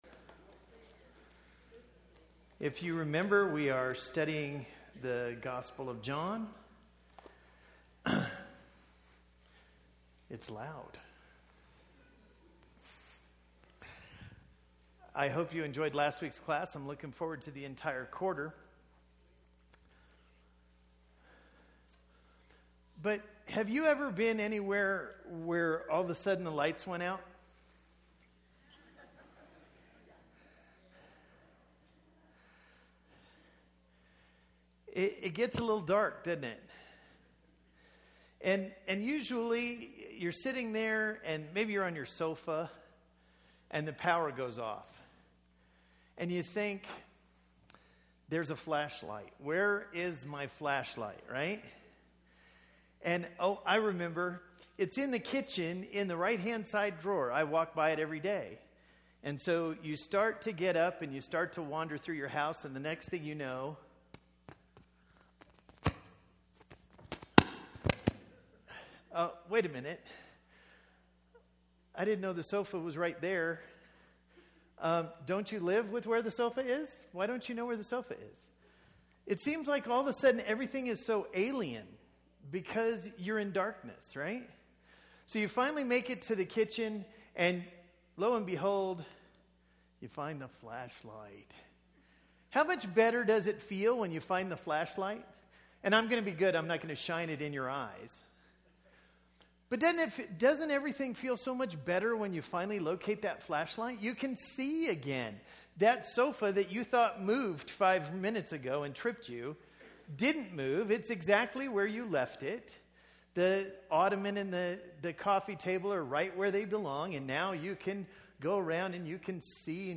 This is a study of the I AM statements of Jesus in the Gospel of John. Tonight's lesson comes from John 8 and 9. These presentations are part of the Wednesday night adult Bible classes at the Bear Valley church of Christ.